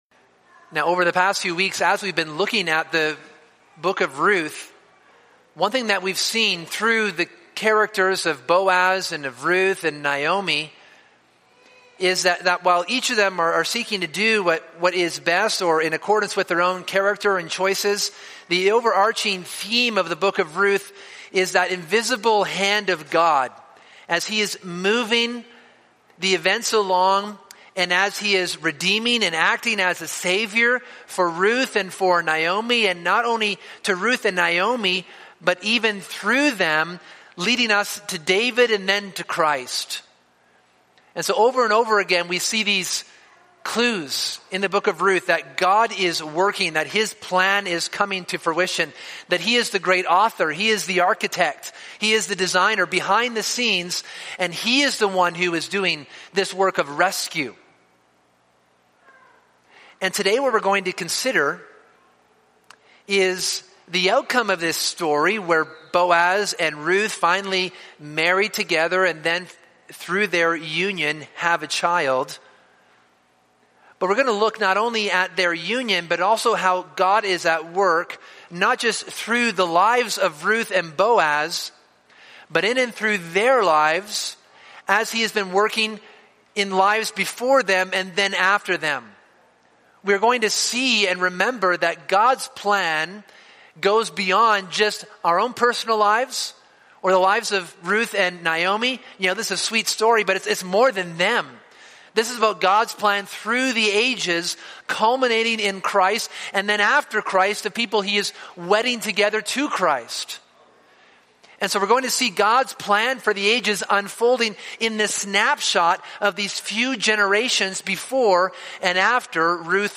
This sermon considers the union between Boaz and Ruth and their offspring after them.